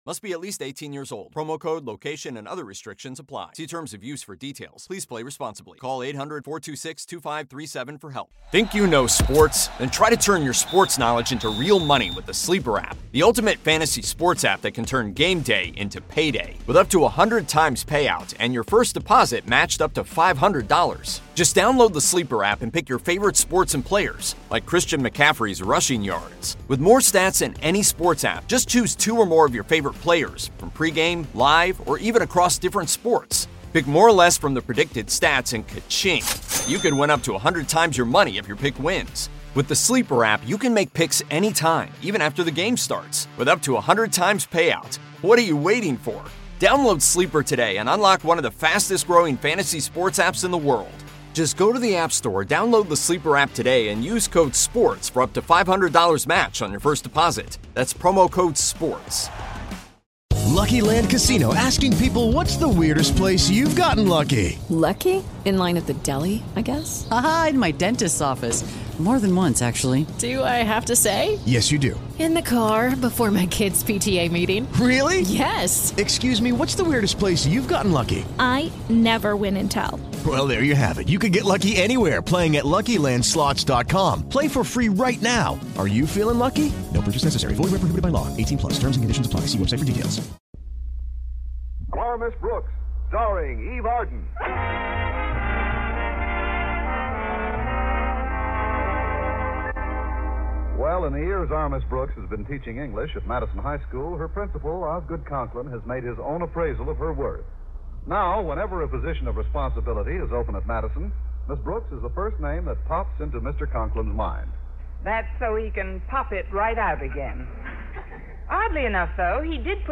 Our Miss Brooks was a beloved American sitcom that ran on CBS radio from 1948 to 1957.
The show starred the iconic Eve Arden as Connie Brooks, a wisecracking and sarcastic English teacher at Madison High School. Arden's portrayal of Miss Brooks was both hilarious and endearing, and she won over audiences with her quick wit and sharp one-liners The supporting cast of Our Miss Brooks was equally memorable.